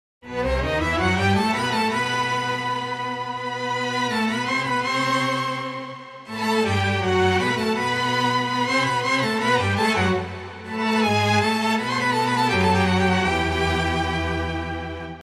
middle eastern style